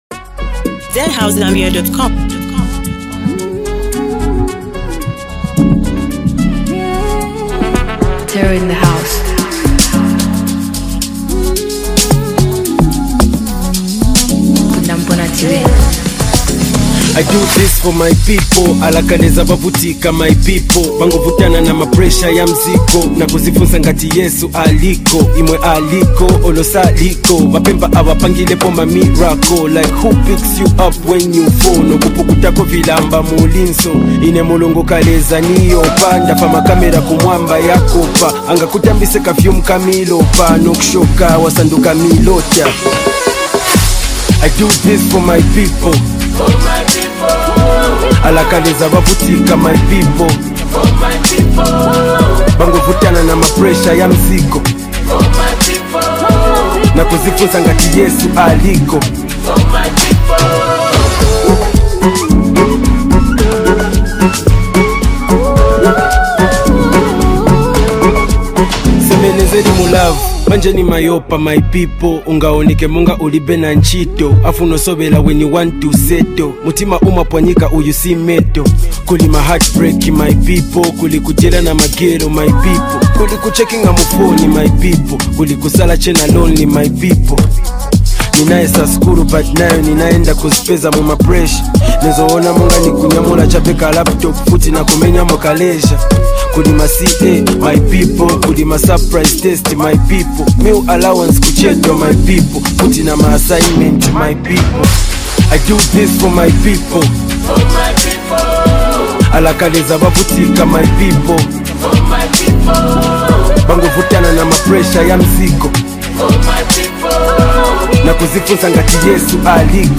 heartfelt anthem
With raw emotion, witty delivery, and relatable storytelling